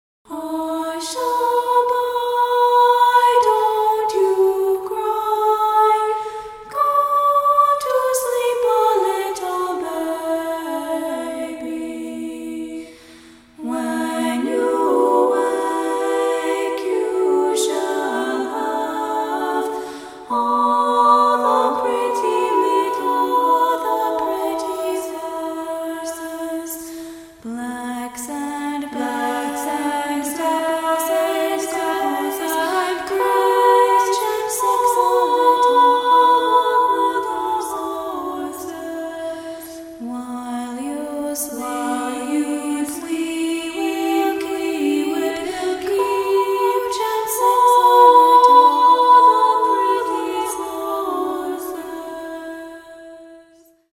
VOCALS
PIANO